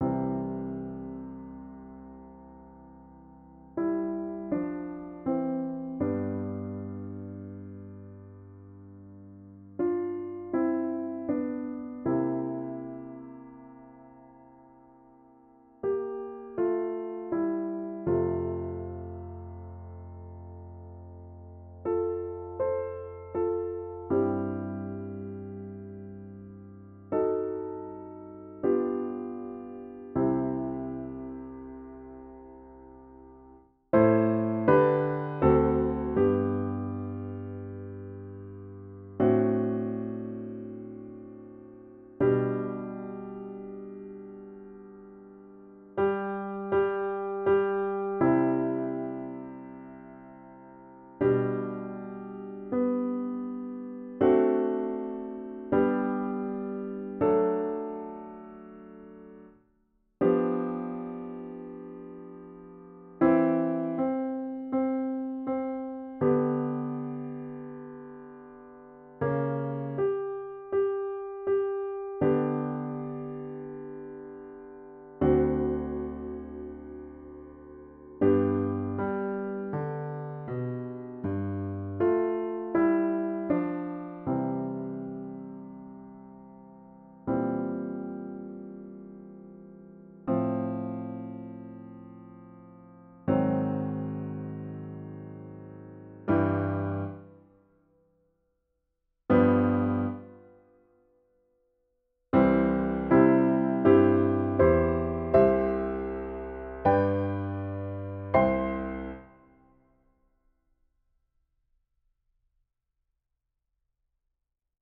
スタインウェイの音をサンプリングしたリアルな音質をお楽しみください
このページの音源は4分音符=60の速さで作ってあります。